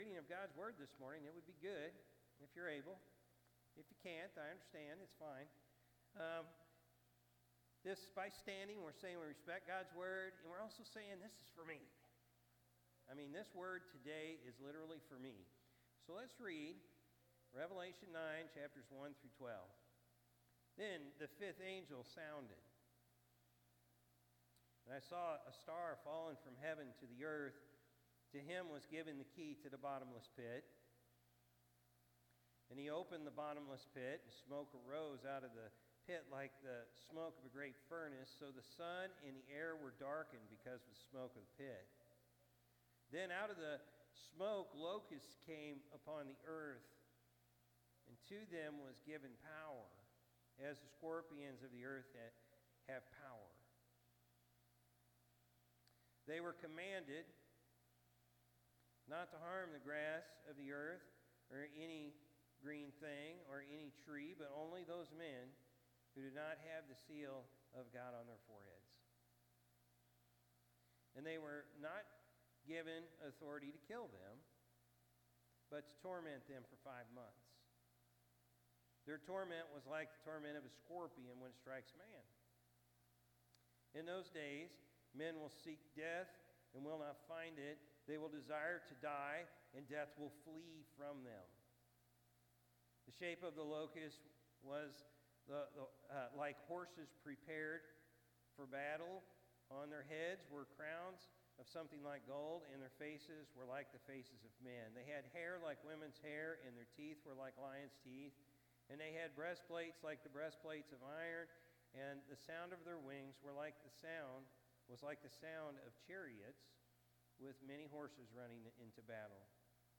October-6-2024-Morning-Service.mp3